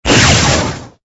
lightning_1.ogg